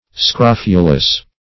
Scrofulous \Scrof"u*lous\, a. [Cf. F. scrofuleux.]